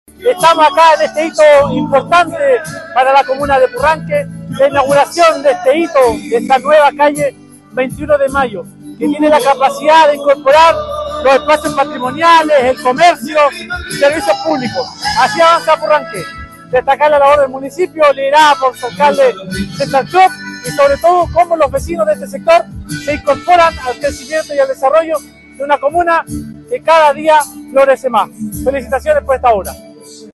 El diputado, Héctor Barría, quien impulsó la iniciativa y apoyo su concreción, precisó que: los vecinos de este sector se incorporan al crecimiento y al desarrollo de una comuna que cada día florece más.